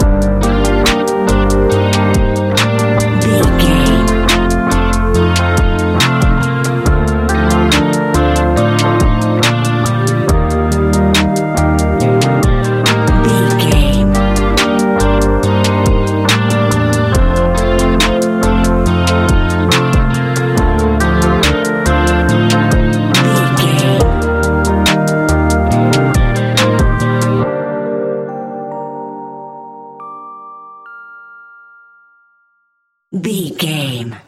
Ionian/Major
A♯
laid back
Lounge
sparse
chilled electronica
ambient
atmospheric